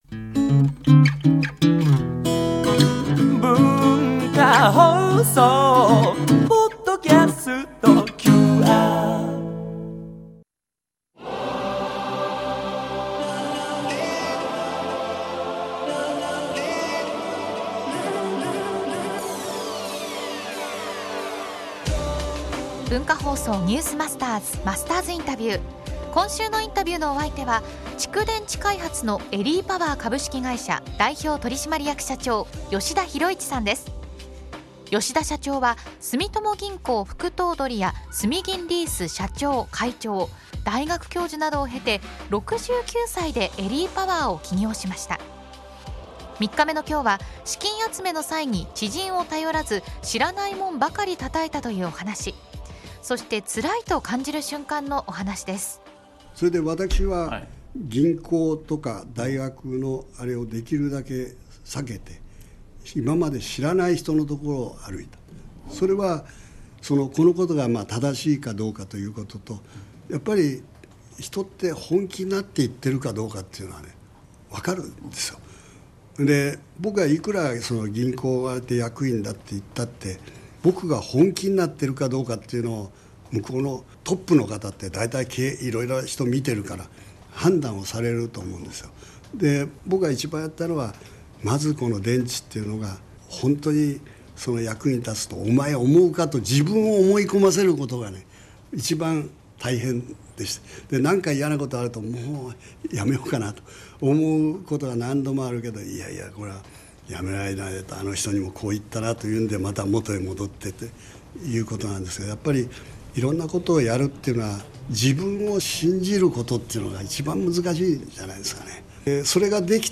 （月）～（金）AM7：00～9：00　文化放送にて生放送！